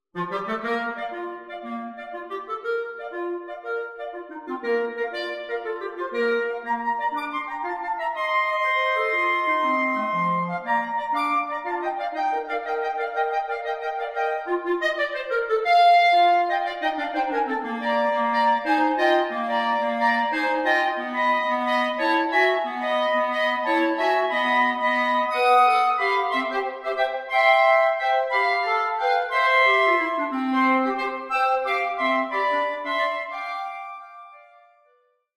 Traditional Folk